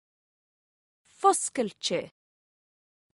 Amazon AWS (pronunciation: fosgailte [NB: Unfortunately I haven't found a link to the noun]).